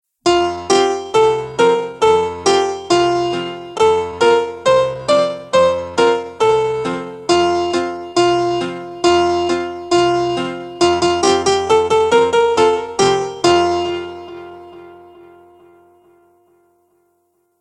原曲メロディーはドイツ民謡と言われています。